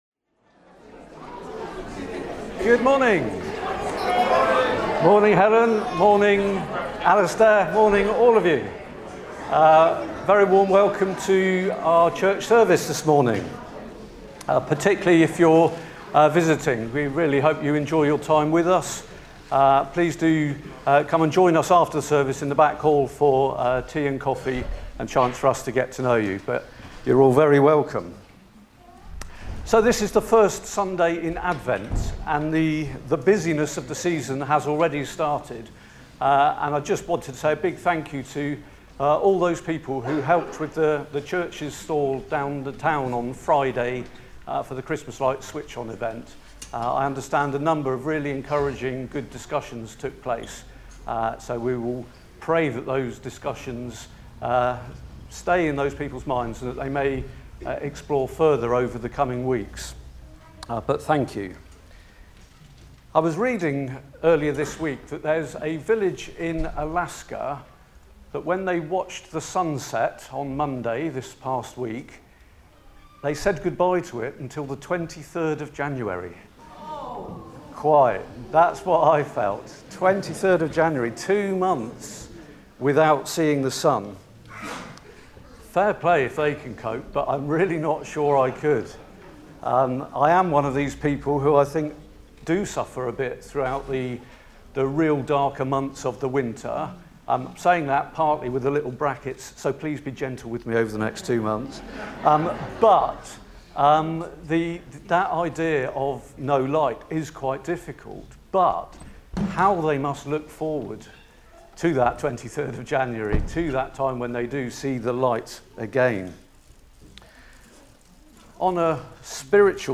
30 November 2025 – Morning Service